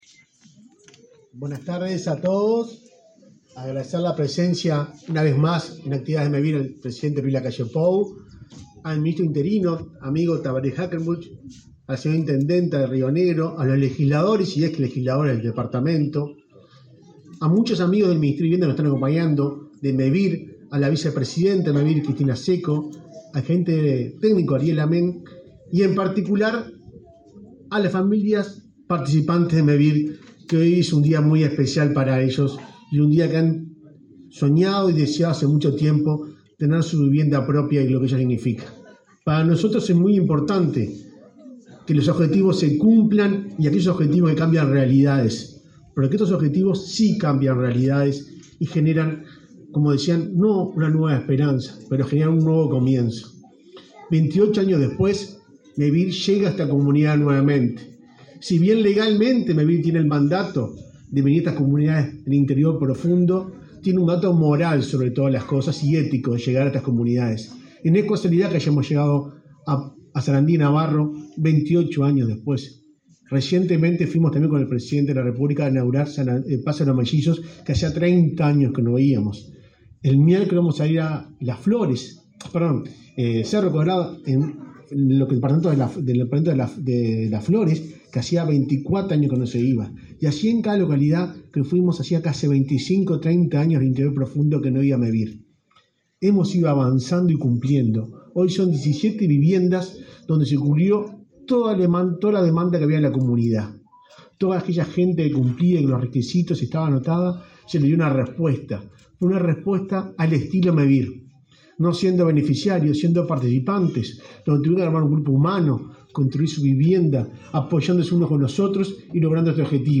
Acto de inauguración de viviendas de Mevir en Sarandí de Navarro
Con la presencia del presidente de la República, Luis Lacalle Pou, se realizó, este 11 de noviembre, la inauguración de 17 viviendas de Mevir en la localidad de Sarandí de Navarro. En el evento participaron el subsecretario del Ministerio de Vivienda y Ordenamiento Territorial, Tabaré Hackenbruch, y el presidente de Mevir, Juan Pablo Delgado.